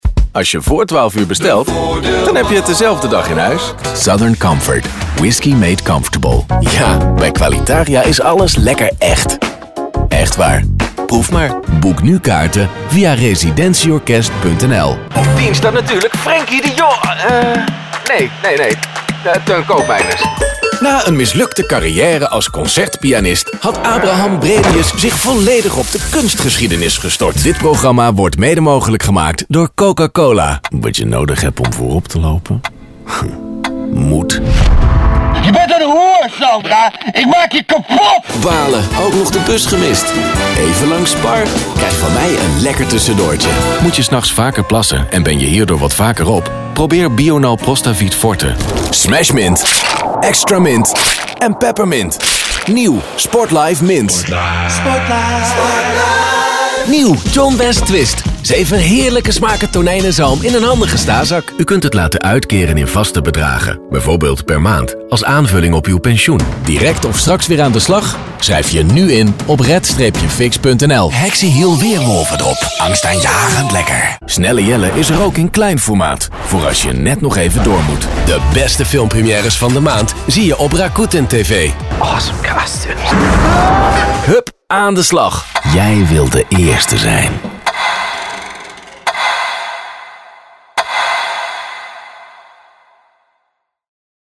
Comercial, Profundo, Amable, Cálida, Empresarial
Comercial